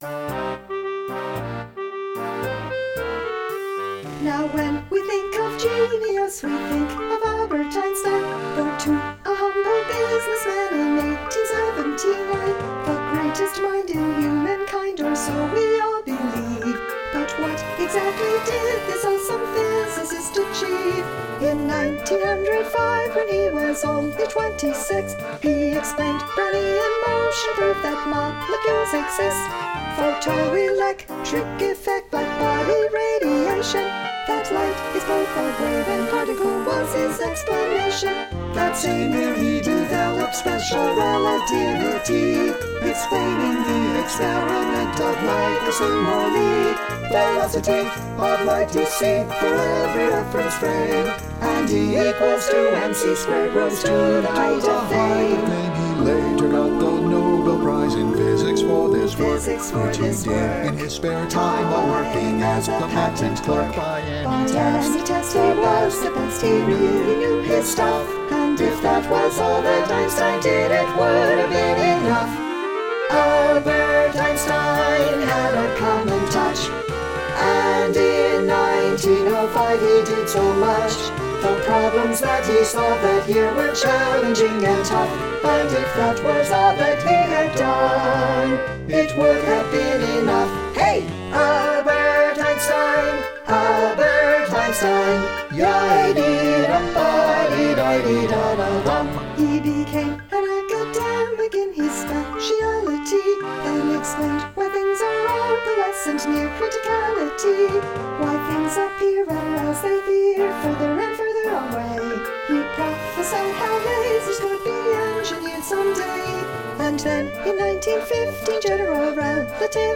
• It Would Have Been Enough (world premiere) - This klezmer paean to German-born theoretical physicist Albert Einstein (1879 - 1955) is based obliquely on the Passover song Dayenu, which appears now and then in the piano accompaniment.
Einstein-DemoWithOrchestra.mp3